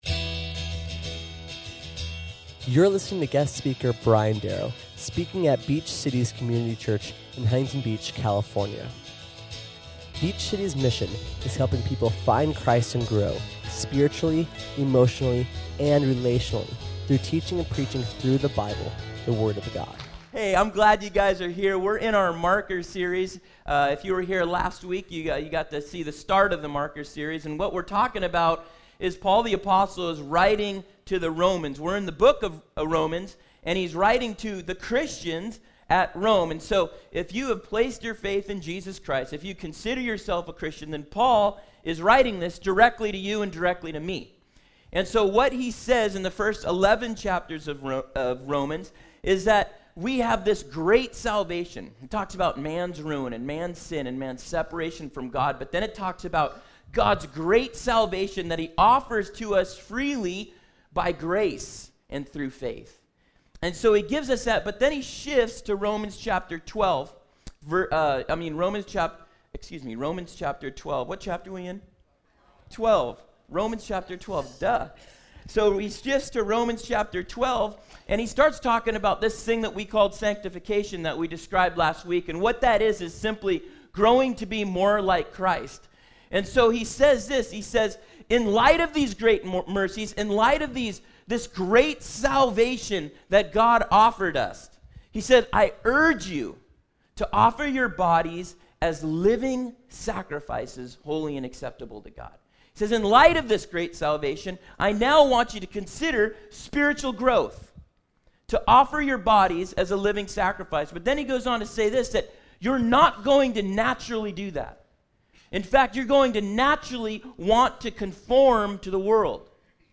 Listen or watch as learn what those obstacles are and what things we can commit to do to keep that zeal ignited. SERMON AUDIO: SERMON NOTES: